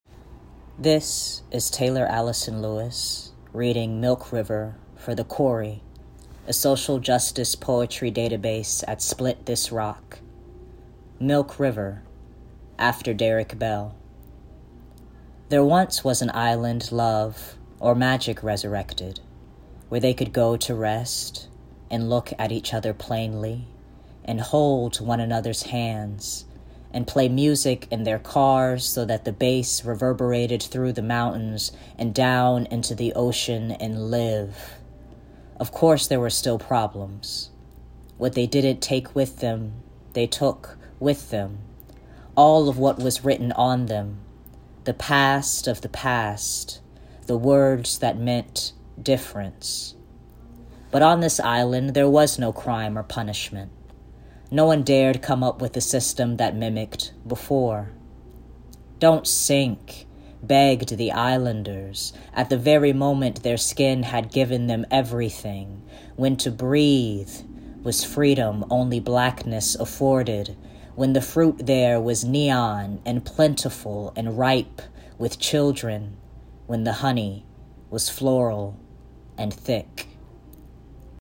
milk river | Poetry Database | Split This Rock